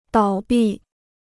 倒闭 (dǎo bì) Free Chinese Dictionary